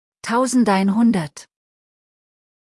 100 (ein)hundert хундэрт